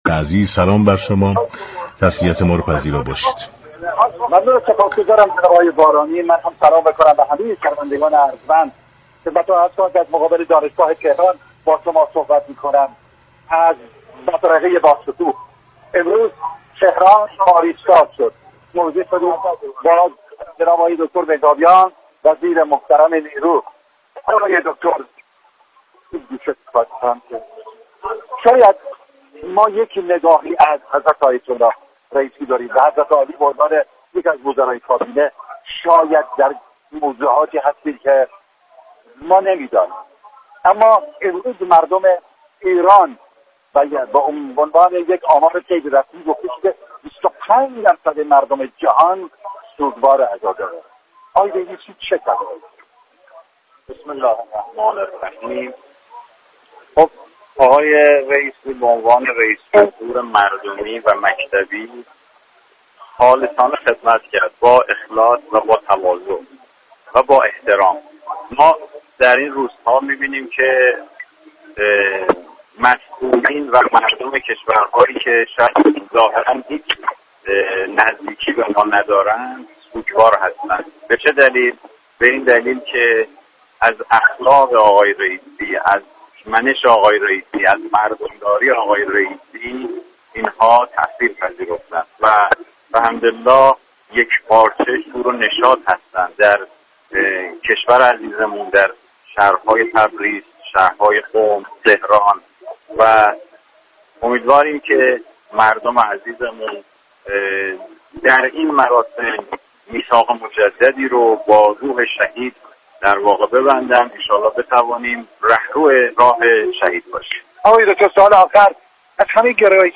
به گزارش پایگاه اطلاع رسانی رادیو تهران، علی اكبر محرابیان وزیر نیرو در گفت و گو با ویژه برنامه «رئیس جمهور» اظهار داشت: شهید سید ابراهیم رئیسی به عنوان رئیس جمهور مردمی و مكتبی؛ خالصانه، متواضعانه و با احترام به مردم ایران خدمت كرد.